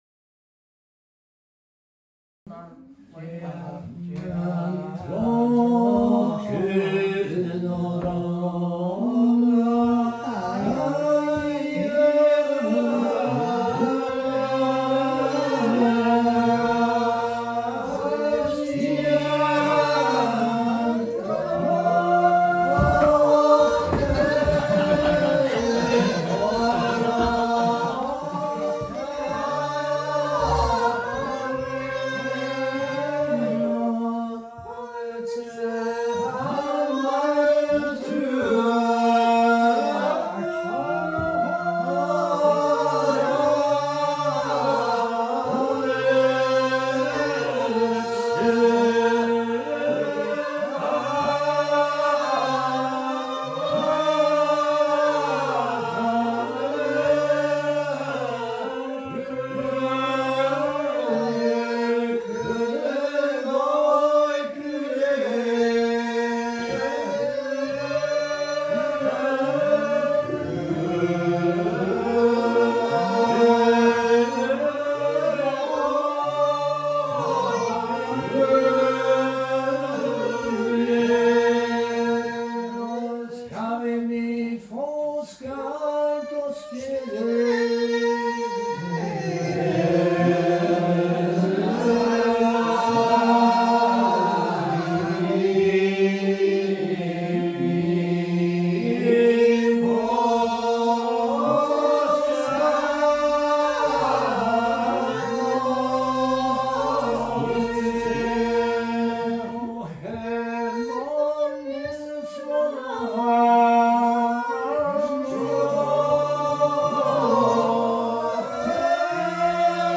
A’ Seinn nan Sailm Gaelic Psalmody